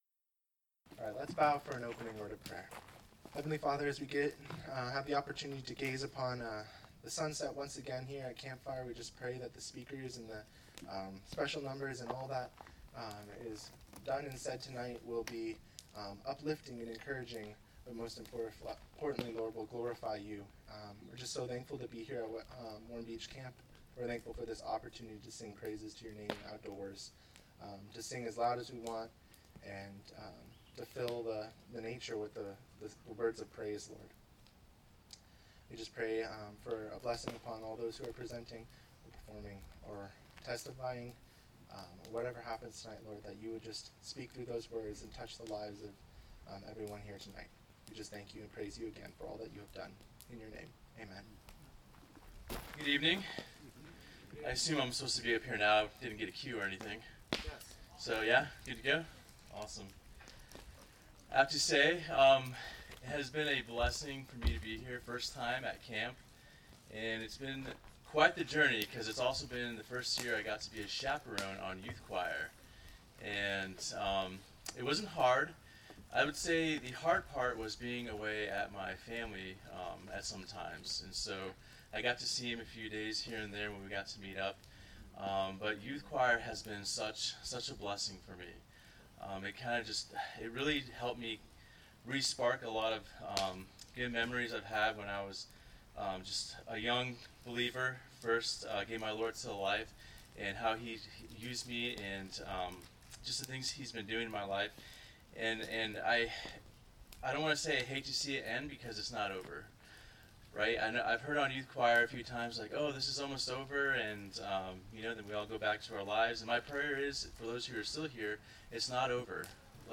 Wednesday Campfire
Wednesday-Campfire-2022.mp3